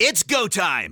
File:Fox voice sample SSBU EN.oga
Fox_voice_sample_SSBU_EN.oga.mp3